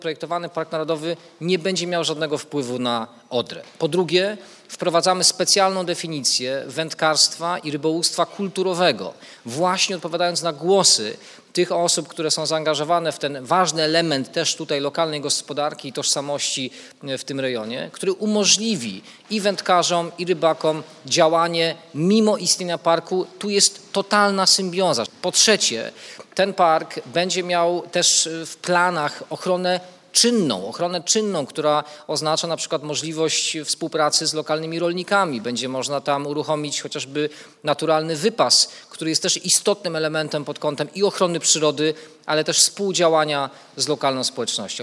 Mikołaj Dorożała wiceminister klimatu i środowiska podkreślał podczas dzisiejszej konferencji prasowej w Szczecinie, że powołanie parku nie będzie miało wpływu np. na działalności rybaków lub wędkarzy.